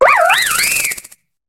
Cri de Manternel dans Pokémon HOME.